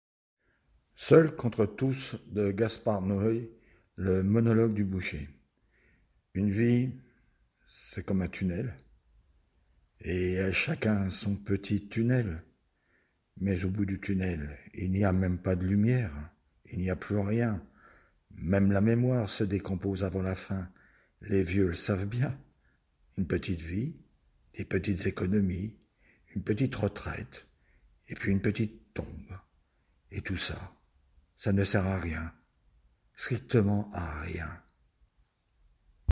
Le monologue du boucher extrait "Seul contre tous" de Gaspard Noé
47 - 59 ans - Contralto